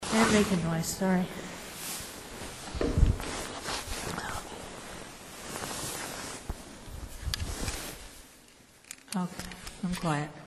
I am moving to a different spot in the church. As I go, my jacket is rustling.  This is odd in that it appears that an EVP comes through my jacket's rustling.